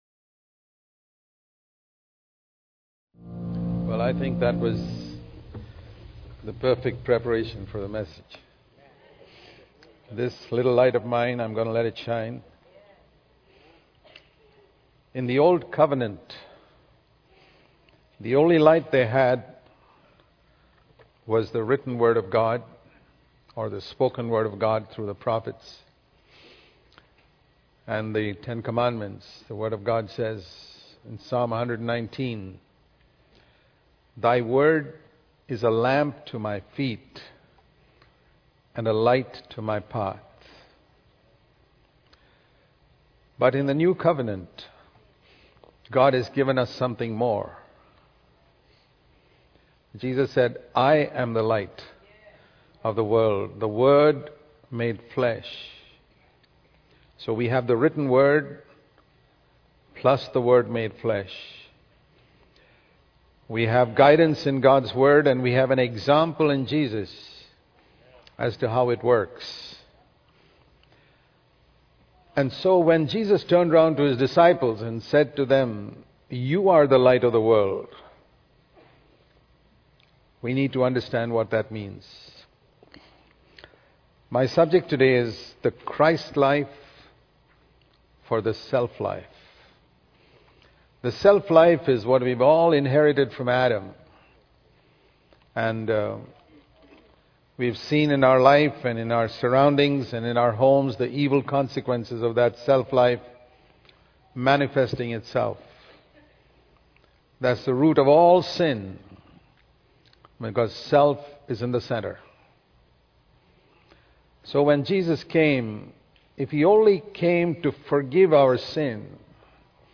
Message spoken